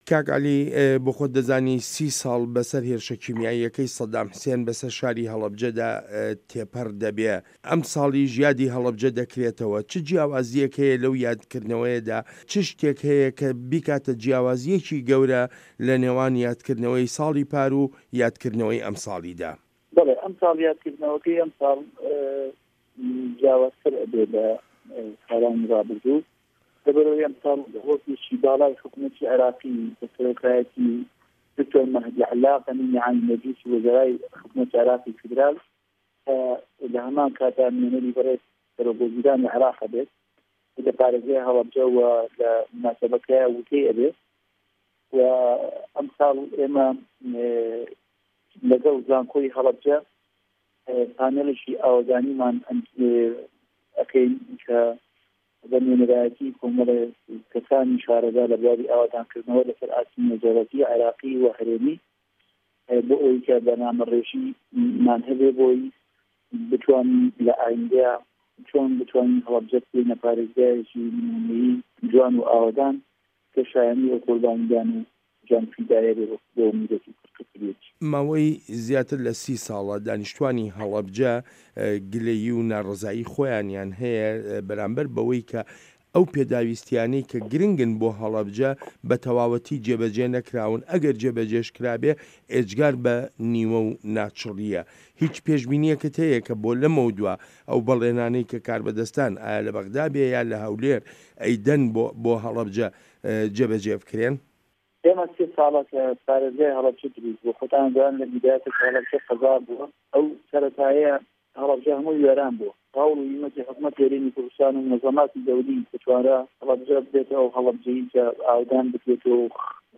وتووێژ لەگەڵ عەلی عوسمان پارێزگاری هەڵەبجە